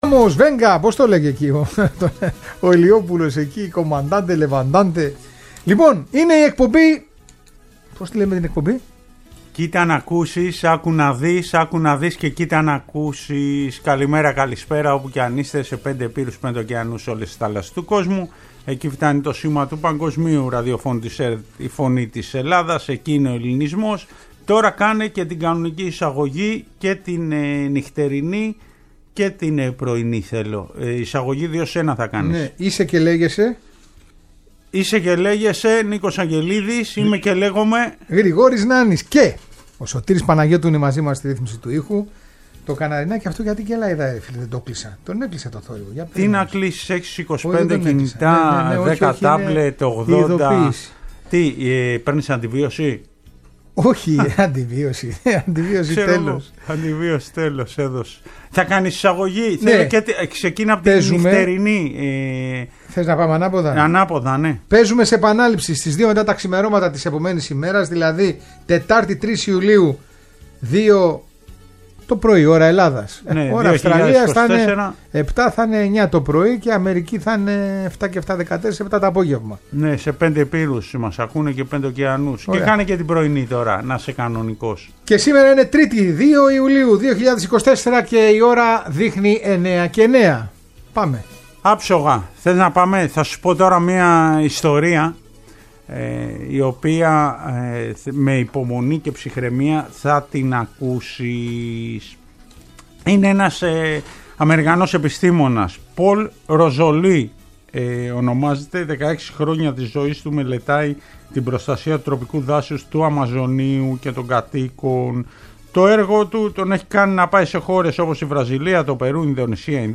Τέλος μαθαίνουμε την ιστορία της Νίκης Πολυγύρου και ακούμε τον ύμνο της ομάδας από την οποία ξεκίνησε ο τερματοφύλακας της ΑΕΚ, Τριαντάφυλλος Στεργιούδας